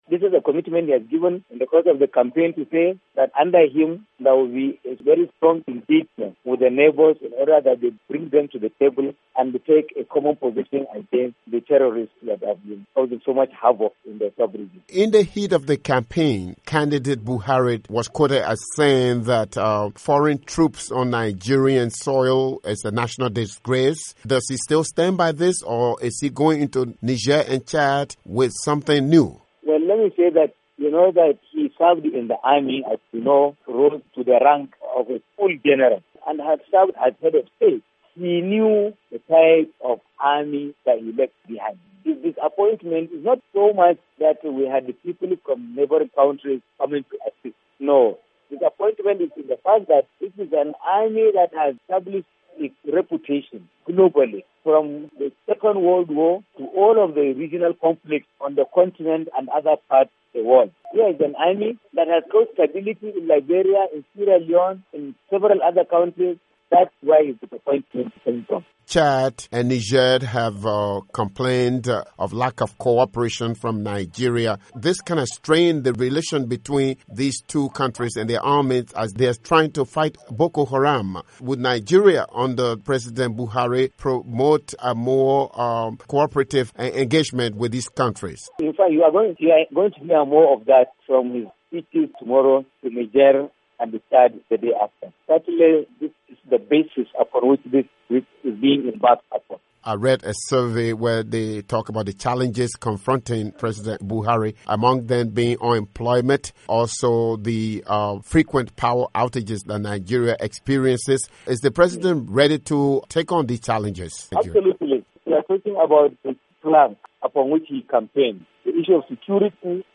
interview with Garba Shehu